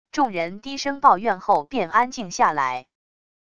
众人低声抱怨后便安静下来wav音频